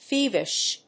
音節thíev・ish 発音記号・読み方
/‐vɪʃ(米国英語)/